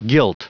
Prononciation du mot guilt en anglais (fichier audio)
Prononciation du mot : guilt